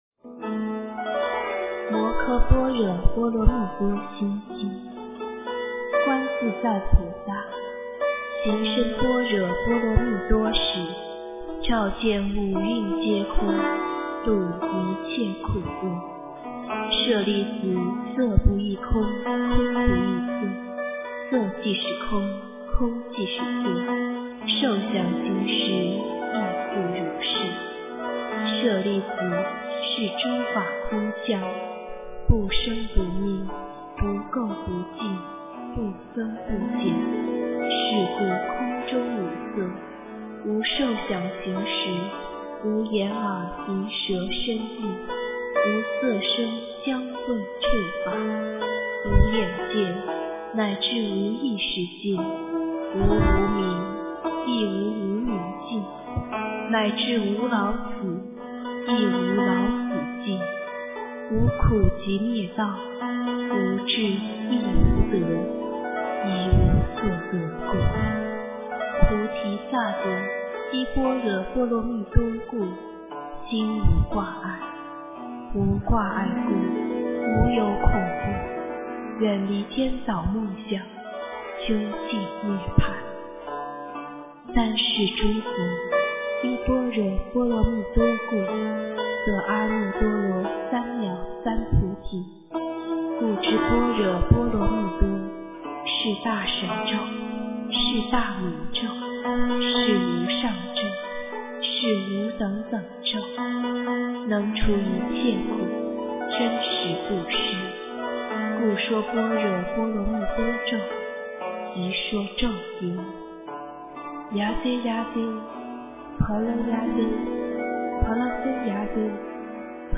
诵经
佛音 诵经 佛教音乐 返回列表 上一篇： 心经 下一篇： 心经-梵音念诵 相关文章 大悲咒 大悲咒--新韵传音...